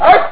Dog 1
DOG_1.wav